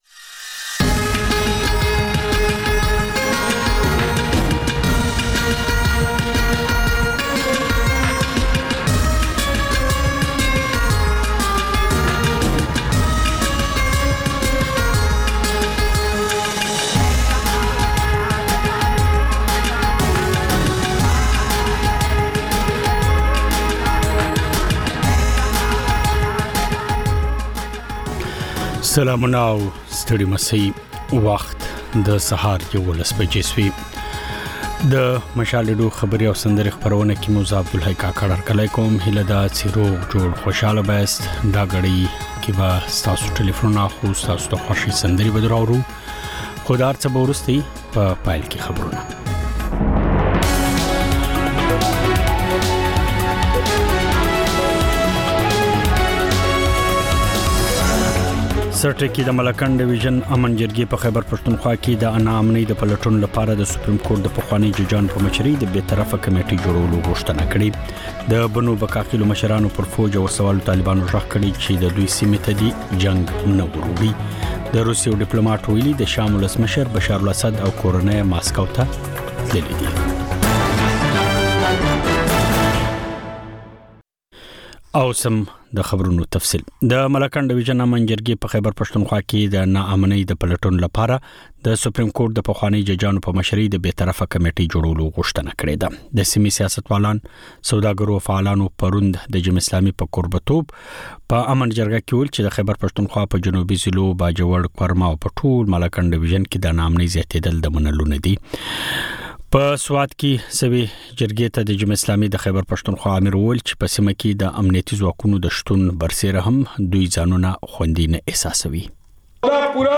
په دې خپرونه کې تر خبرونو وروسته له اورېدونکو سره په ژوندۍ بڼه خبرې کېږي، د هغوی پیغامونه خپرېږي او د هغوی د سندرو فرمایشونه پوره کول کېږي.